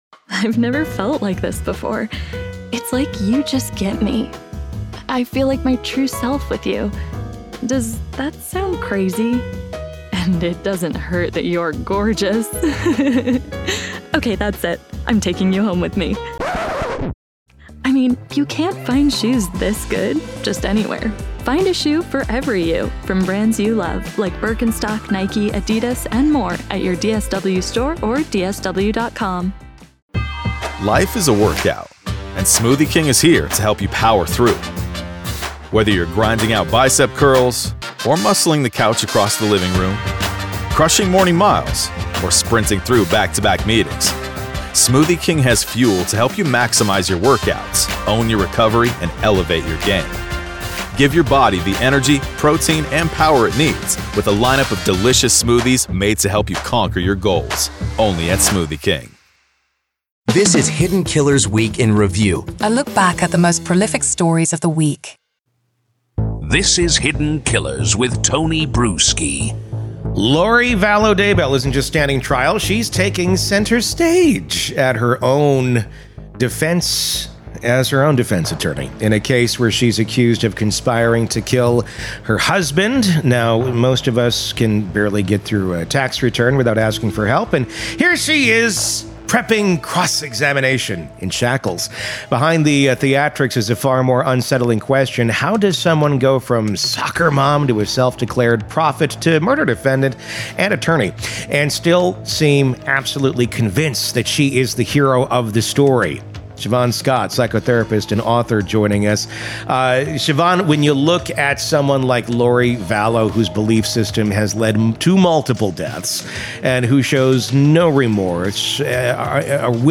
Psychotherapist Breaks Down What To Expect From Lori Vallow Daybell As New Trial Begins-WEEK IN REVIEW